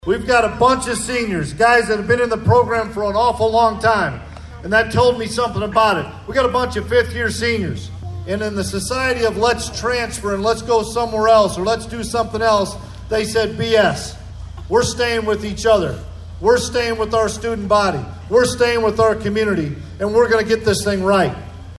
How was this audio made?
The Kansas State University pumped up the crowd on night one of Purple Power Play in the Park Thursday night as hundreds packed Manhattan City Park.